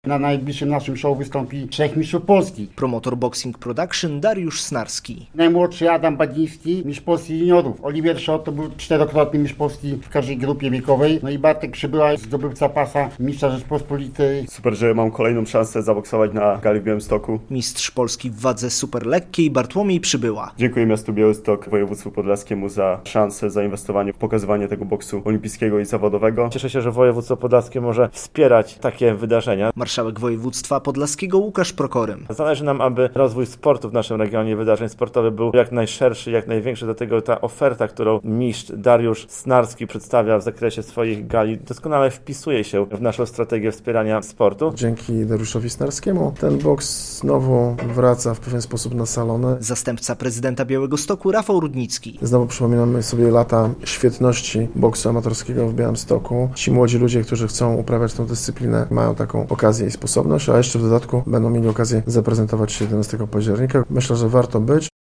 Konferencja prasowa zapowiadająca Białystok Boxing Show 9, 2.09.2025, fot.